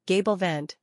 gay · bl - vent